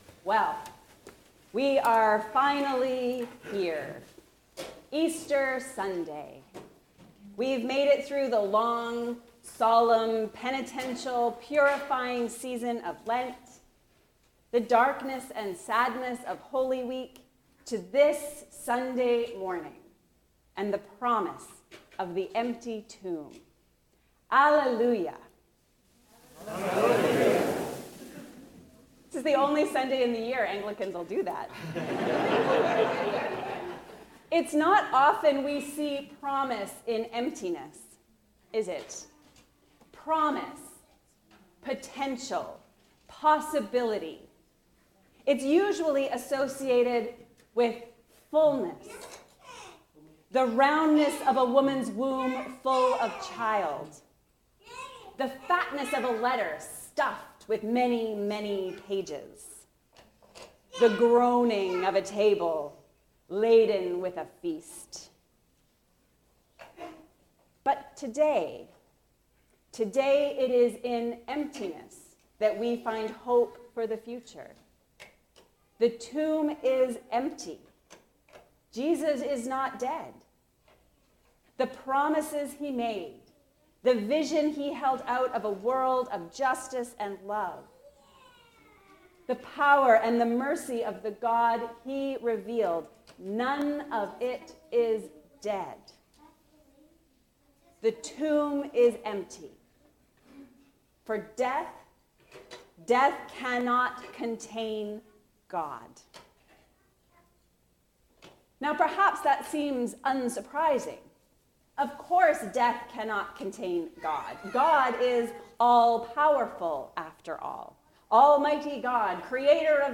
The tomb is empty. A sermon for Easter Sunday